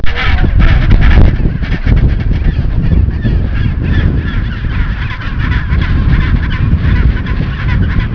The massive chinstrap penguin rookery
Penguin sweethearts greeting with a neck rub